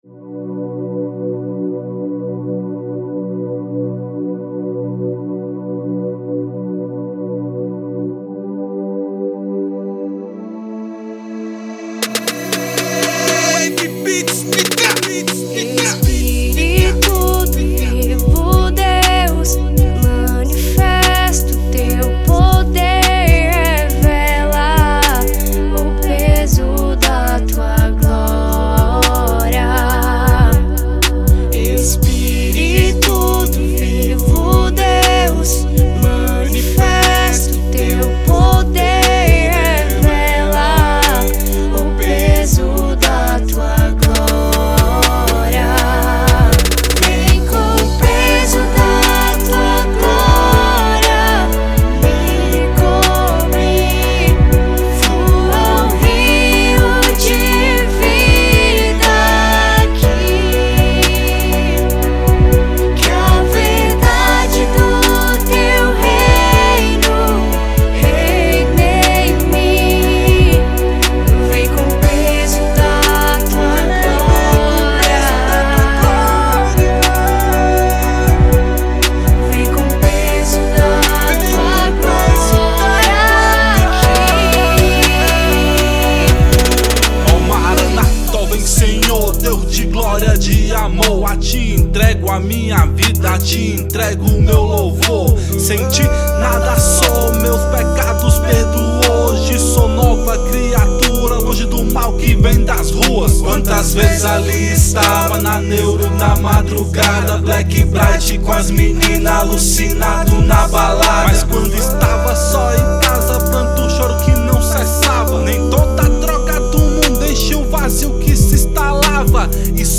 (rap)